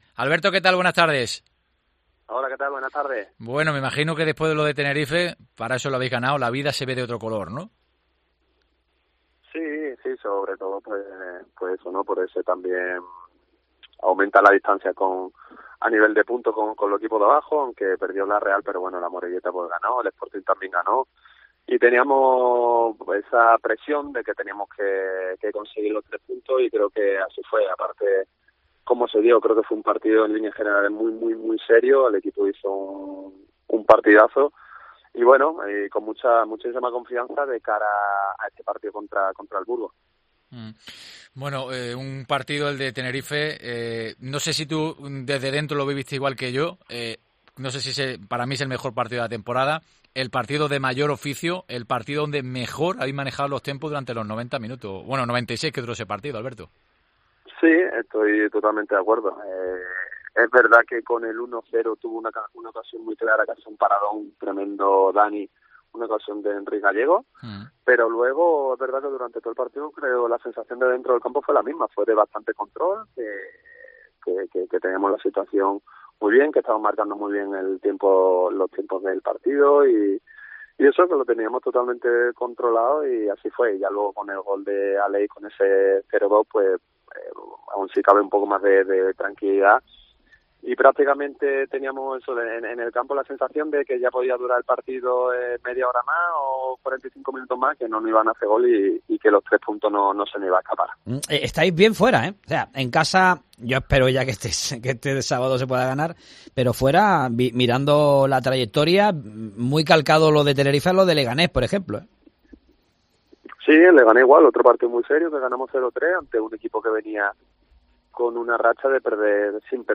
Hay que ganar, pero desde la tranquilidad, no podemos estar nerviosos ni querer cerrar el partido en el minuto 10 ", comentaba en una entrevista en Deportes COPE Málaga , en el que habló de todo.